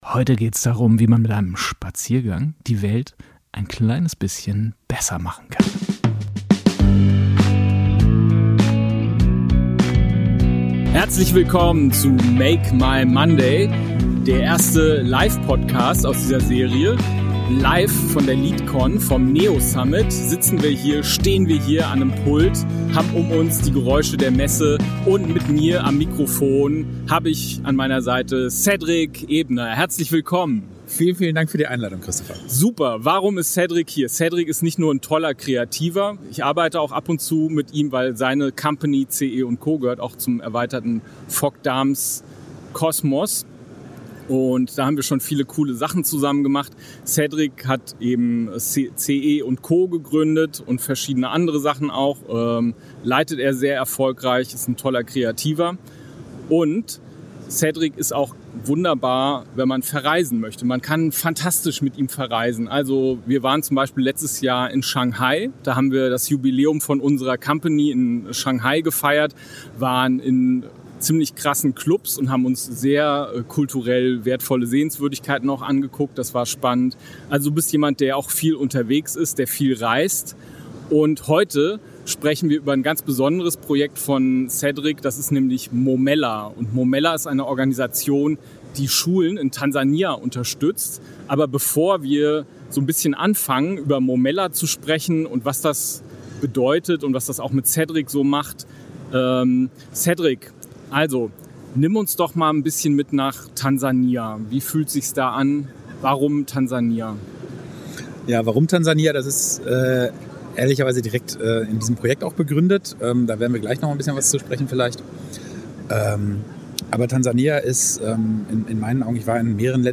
Diese besondere Live-Episode kommt direkt von der NEOSummit/LEaTcon in Hamburg – mit Messeatmo, Publikum und einem Gast, der Wirkung statt Worthülsen liefert.